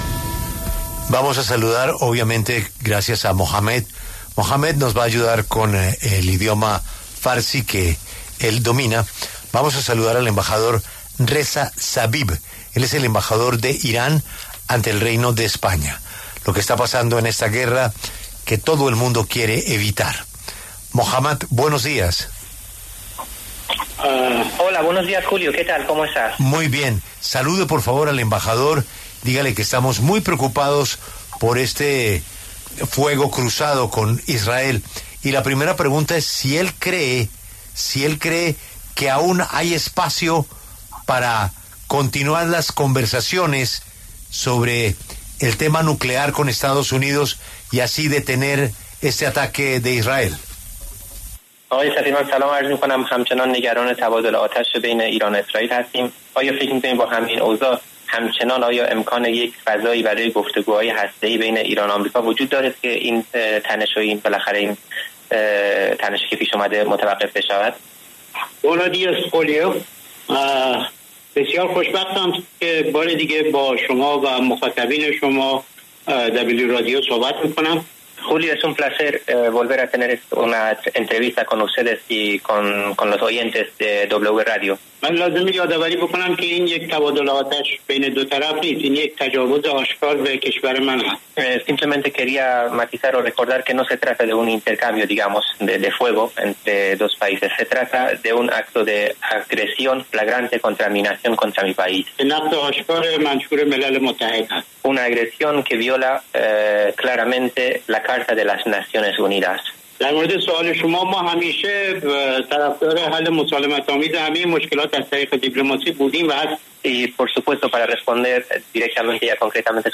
Reza Zabib, embajador de Irán en España, habló en La W sobre las negociaciones nucleares con Estados Unidos, la nueva escalada en el conflicto entre Israel e Irán y la posibilidad de un guerra total en Oriente Medio.